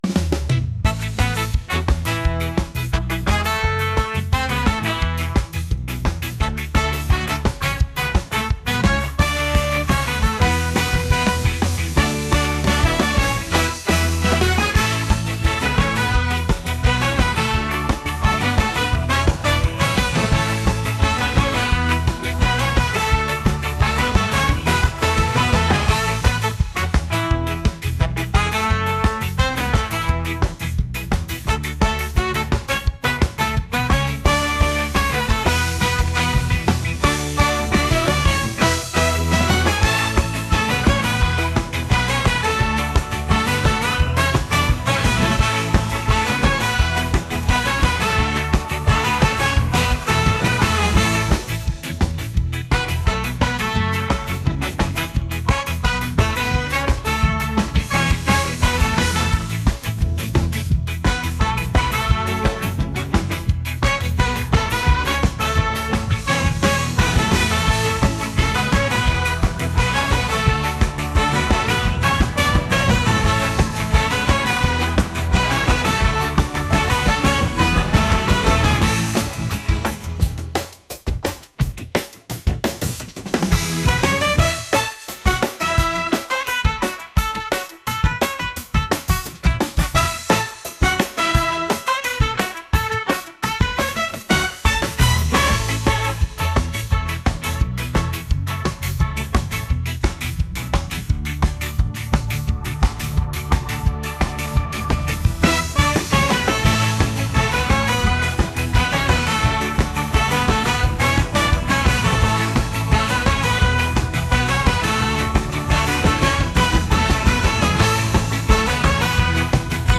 reggae | ska